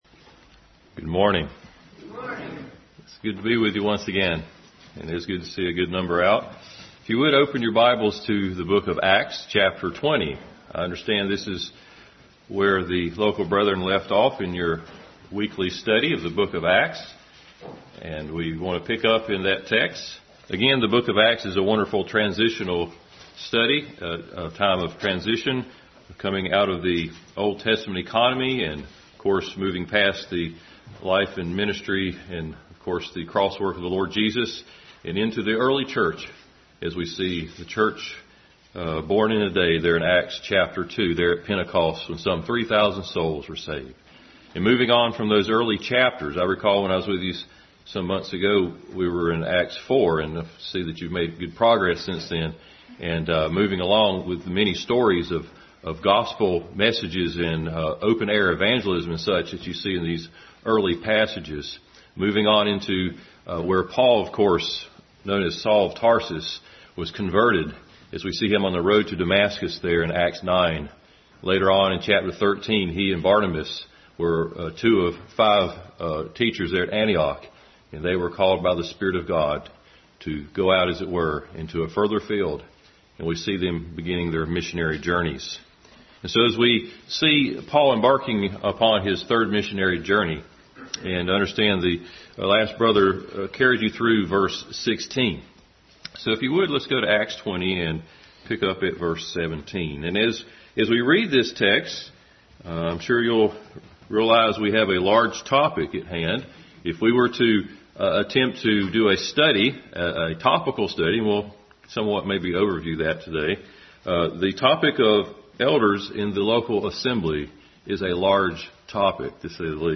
Bible Text: Acts 20:17-38 | Weekly Sunday School hour. Continued study in the book of Acts.
Acts 20:17-38 Service Type: Sunday School Bible Text